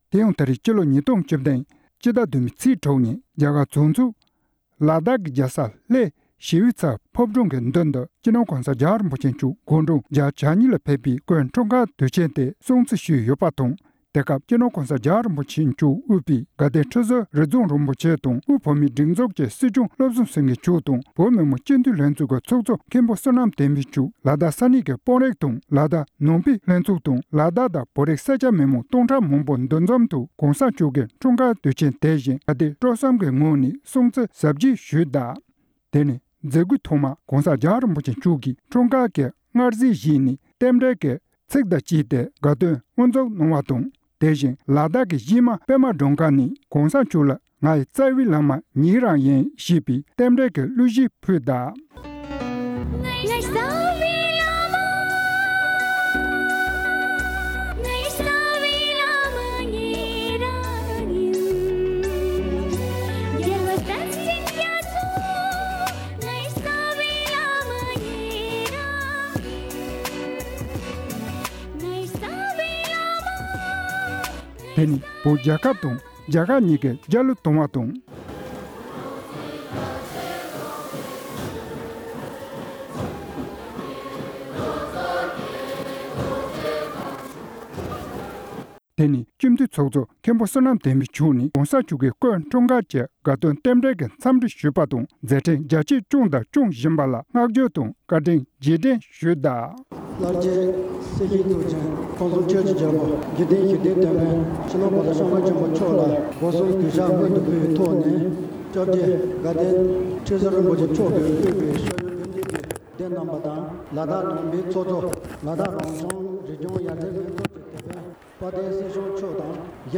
༧གོང་ས་མཆོག་དགུང་གྲངས་༨༢ ལ་ཕེབས་པའི་སྐུའི་འཁྲུངས་སྐར། ལ་དྭགས། སྒྲ་ལྡན་གསར་འགྱུར།